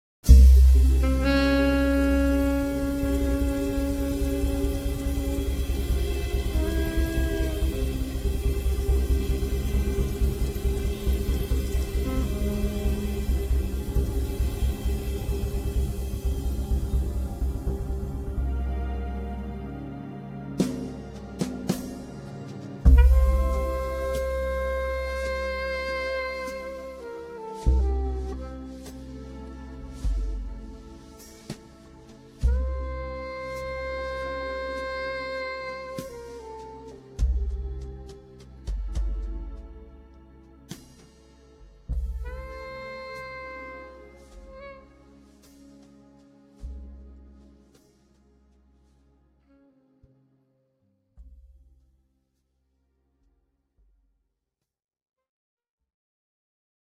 vocals, alto saxophone, bata drum, congas
keyboards
bass
drums